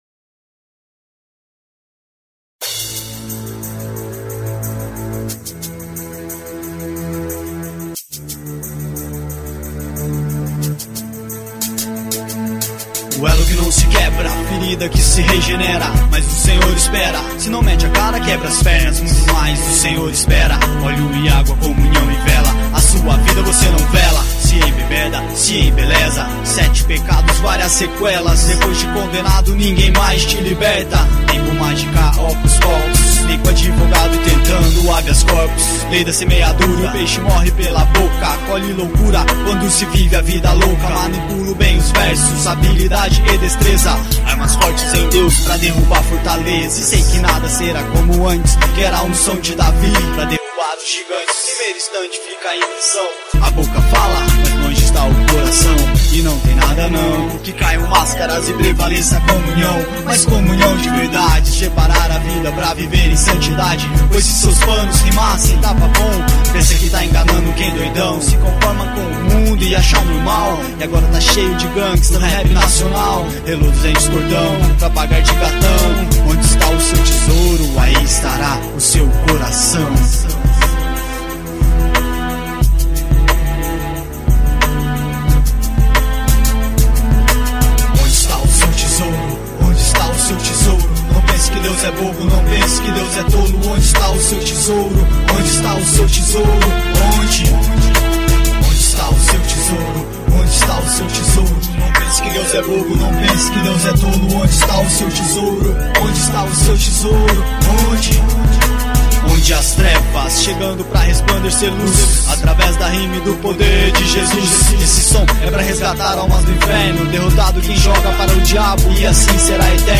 rap gospel.